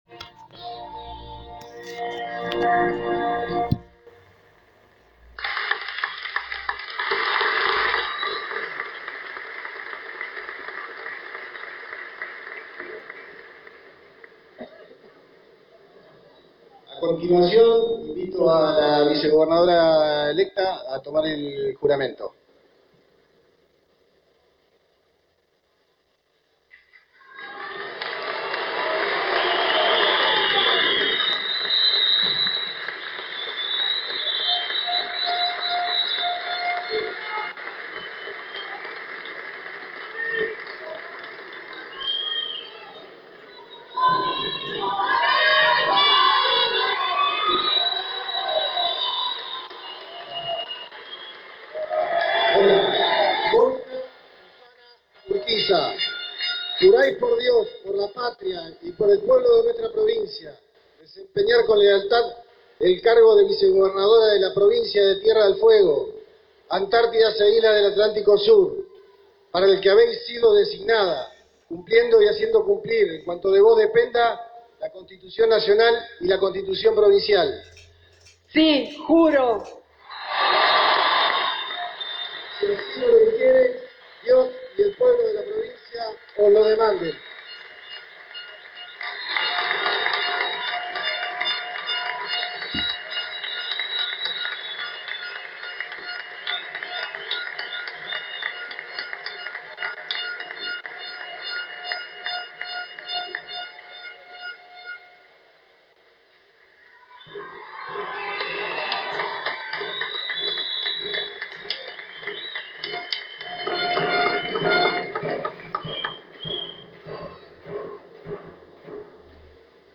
A parir de las 17 hs, tal y como estaba previsto el Profesor Gustavo Melella y Monica Urquiza juraron como Gobernador y Vice, respectivamente en un gimnasio Cochocho Vargas colmado por militantes, publico en general, los tres intendentes de la provincia, Vuoto, Perez y Harringnton e integrantes de los gabinetes provinciales y municipales.